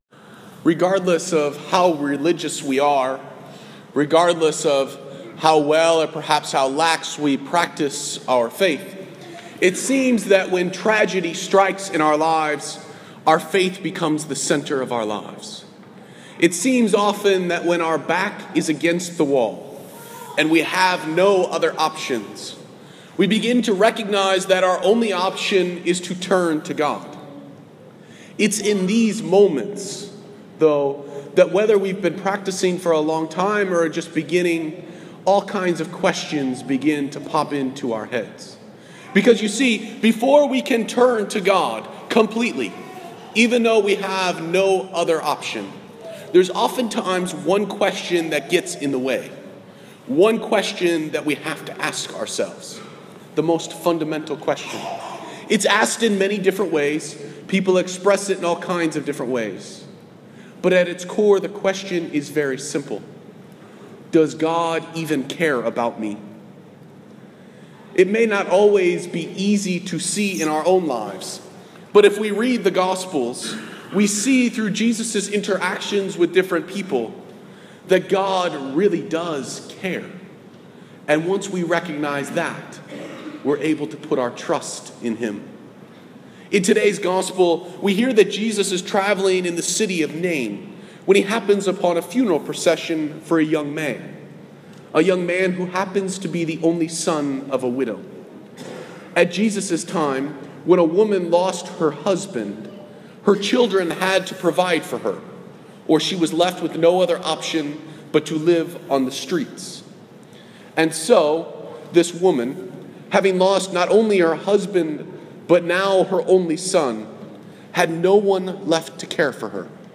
Posted in Homily
sunday-homily.m4a